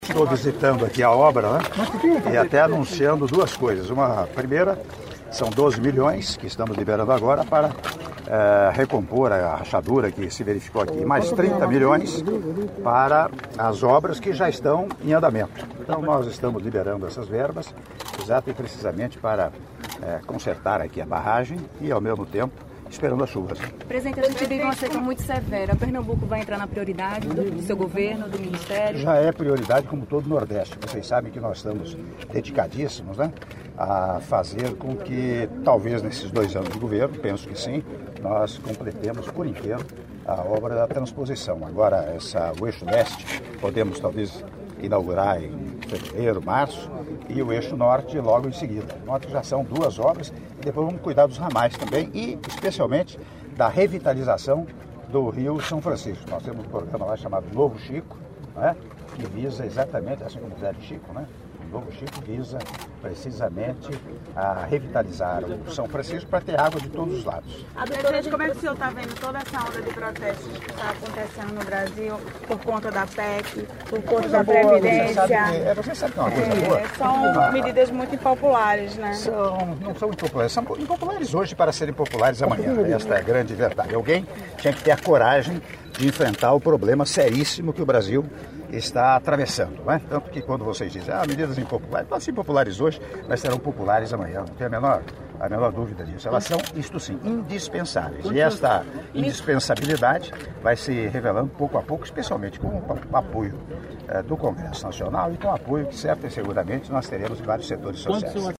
Áudio da entrevista do presidente da República, Michel Temer, após inspeção e assinatura de Atos na Barragem de Jucazinho - Surubim/PE(01min48s)